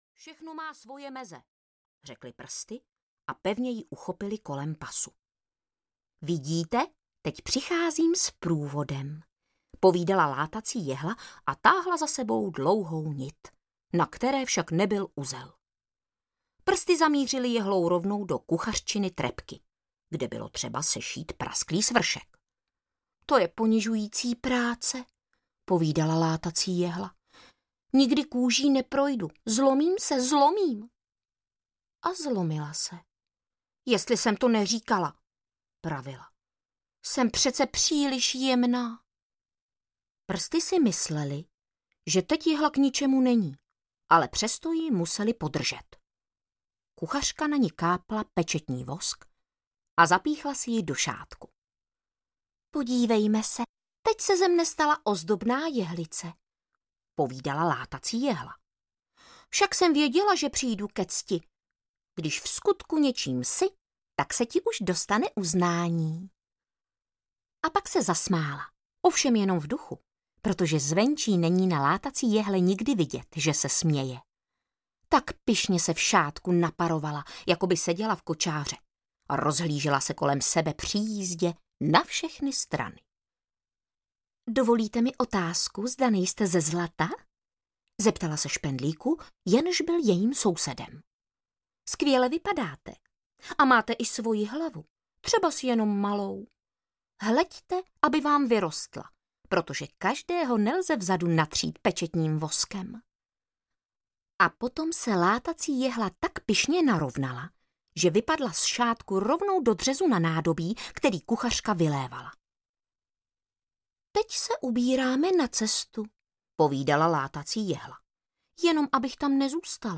Látací jehla audiokniha
Ukázka z knihy